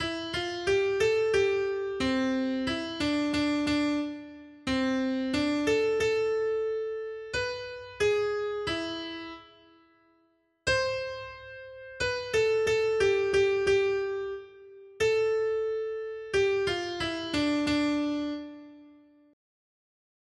Noty Štítky, zpěvníky ol317.pdf responsoriální žalm Žaltář (Olejník) 317 Skrýt akordy R: Sešli svého ducha, Hospodine, a obnovíš tvář země. 1.